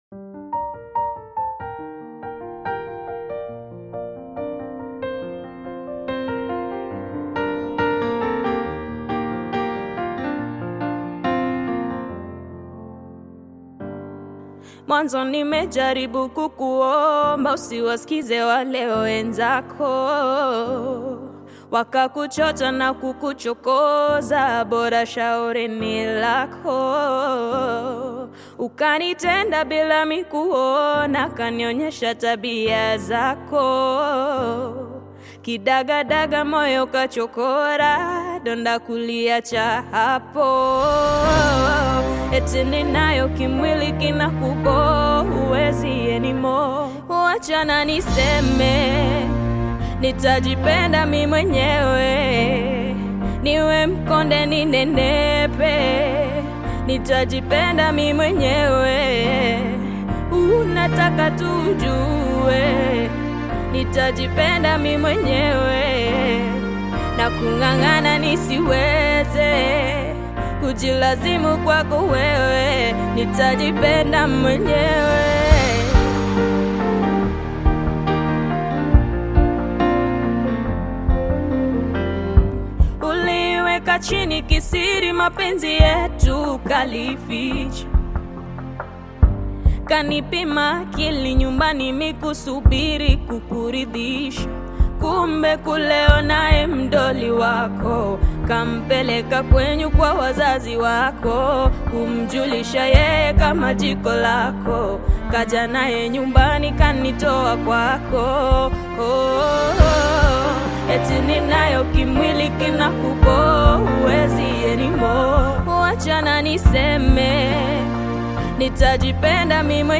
Upcoming bongo flava artist
African Music